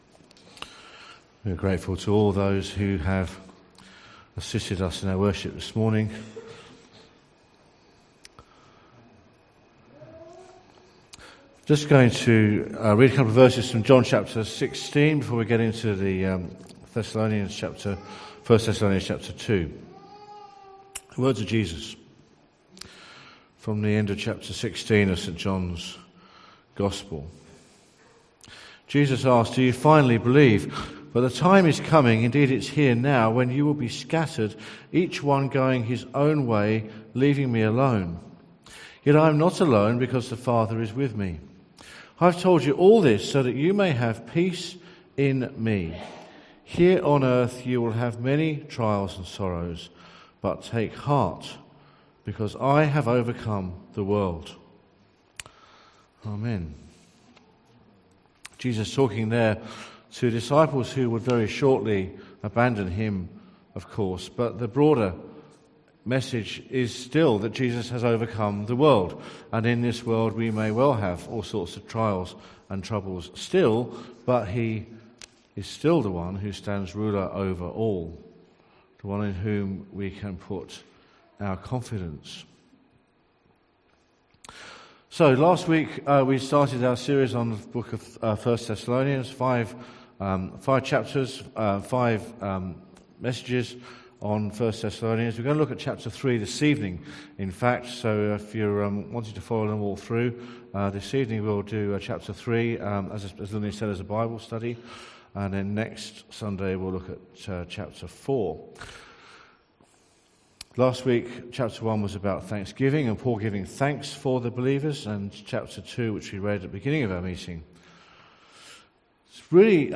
Sermon from the 10AM meeting at Newcastle Worship 7 Community Centre of The Salvation Army. The sermon relates to 2 Thessalonians 2.